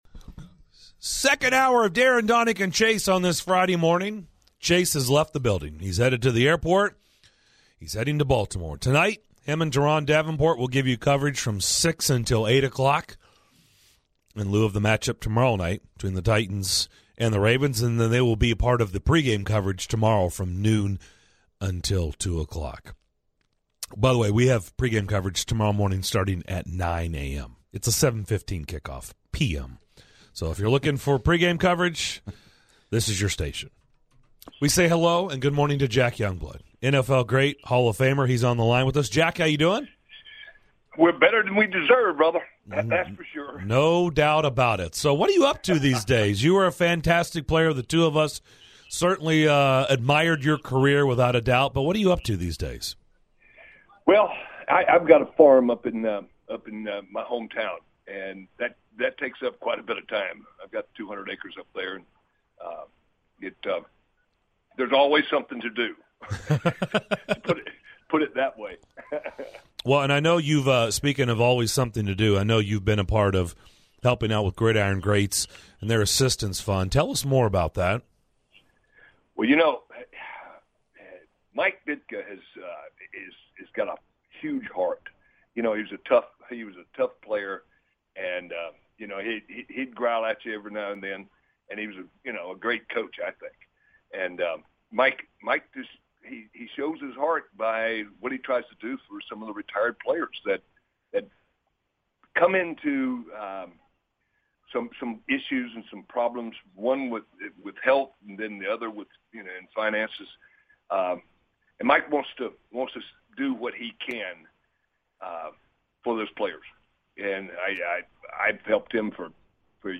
In the second hour of today's show, NFL hall of fame LB Jack Youngblood joined the show to preview the Titans - Ravens matchup. Former Ravens WR Qadry Ismail also joined to give his perspective on the Ravens offense.